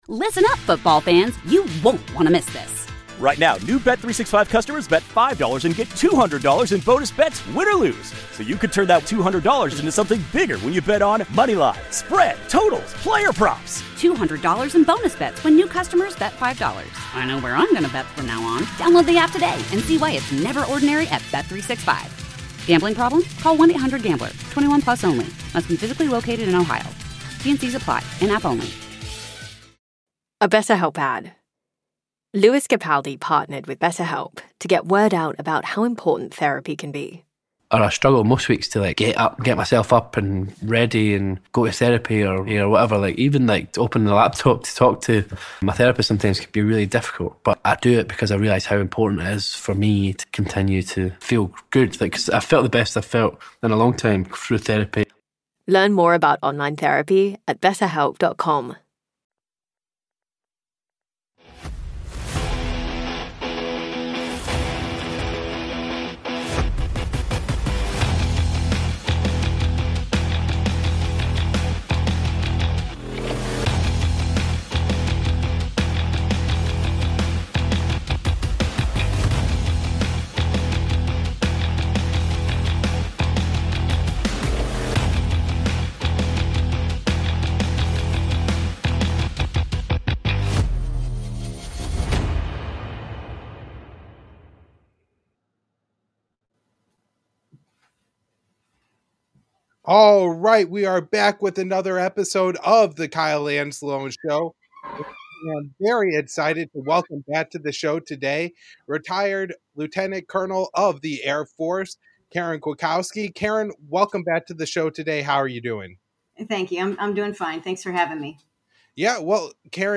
A startling conversation about the rise of political violence at home and abroad.